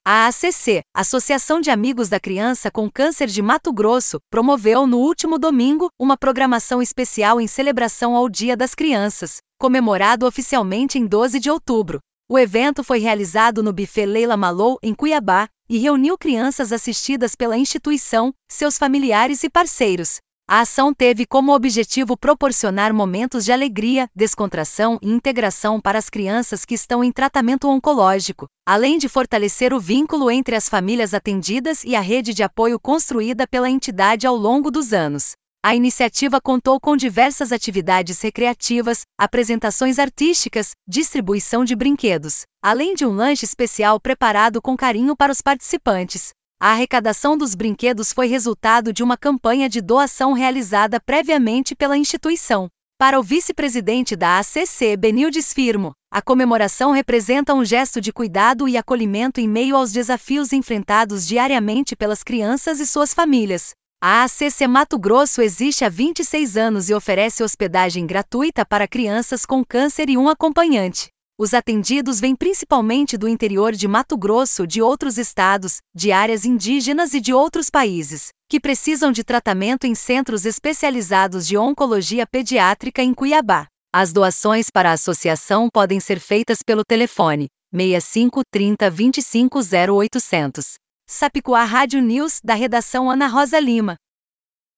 Boletins de MT 07 out, 2025